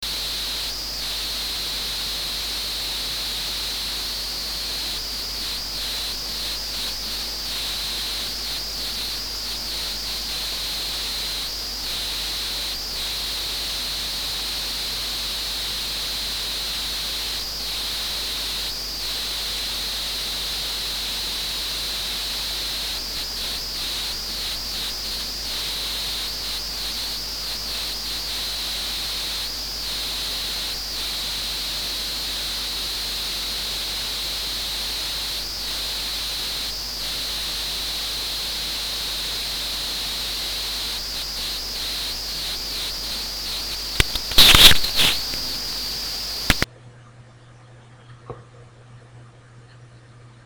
Ambient silent subliminal